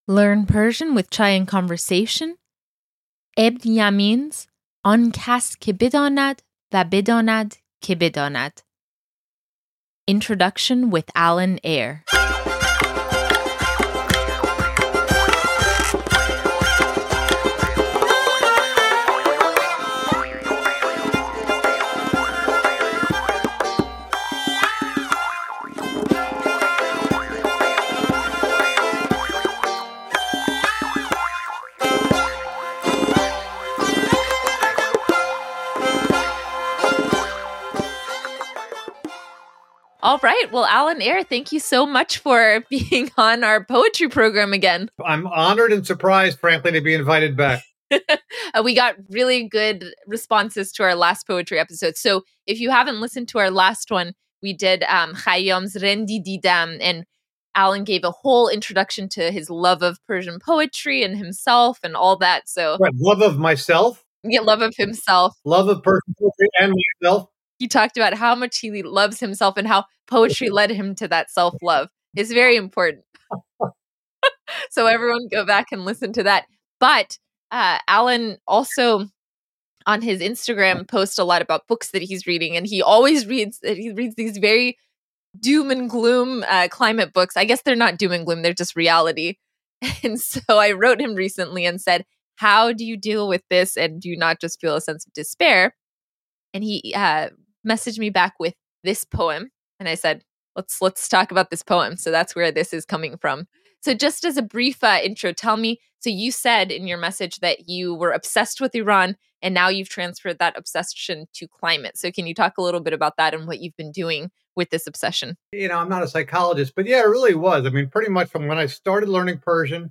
ān kas ké bedānad va bedānad ké bedānad: Intro - Persian Poetry